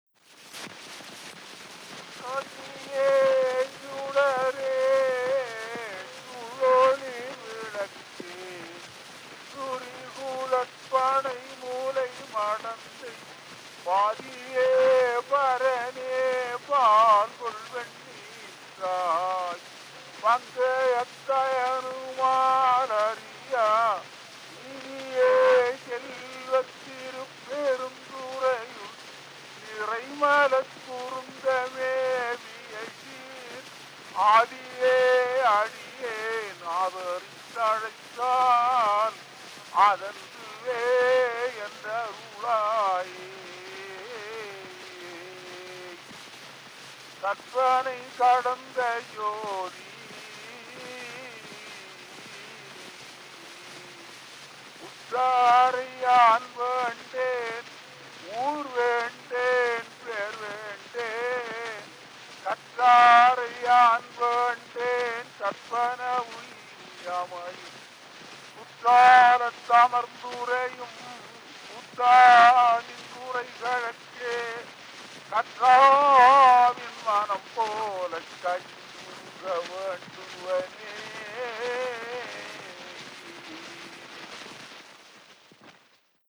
Historical sound recordings